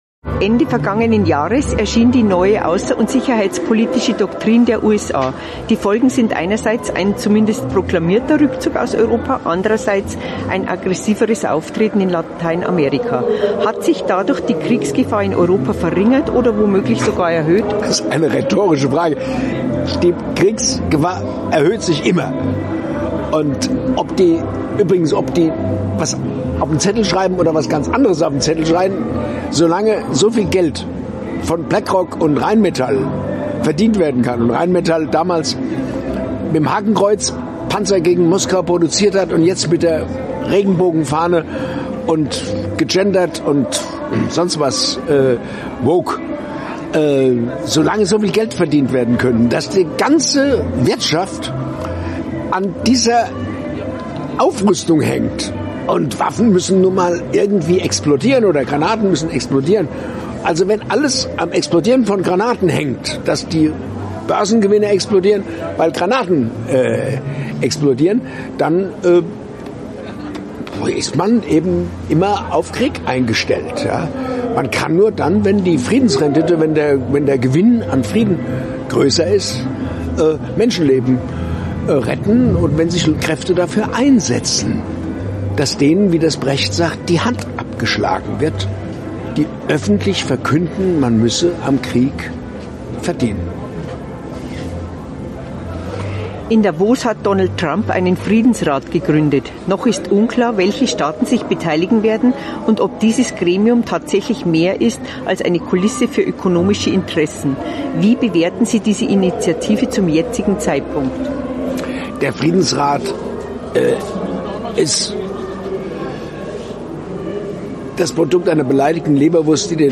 Über die aktuelle Kriegsgefahr spricht der langjährige Bundestagsabgeordnete und Musiker Diether Dehm mit AUF1.